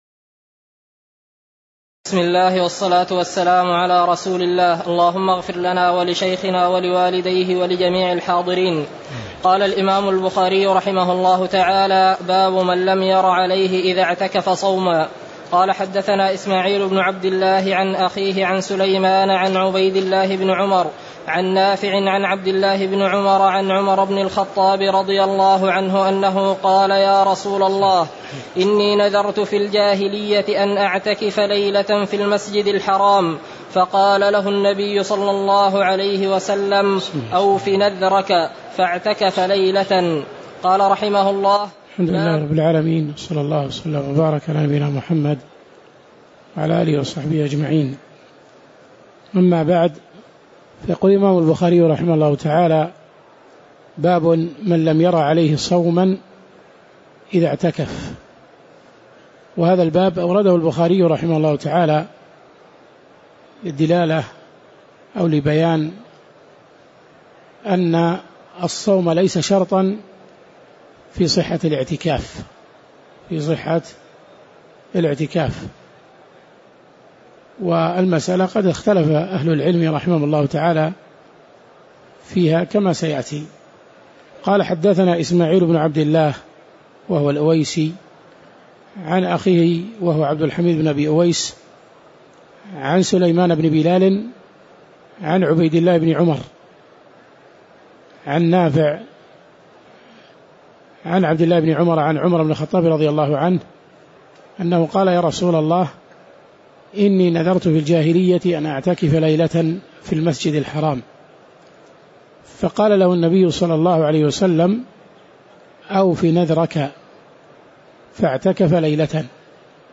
تاريخ النشر ٢٠ رمضان ١٤٣٨ هـ المكان: المسجد النبوي الشيخ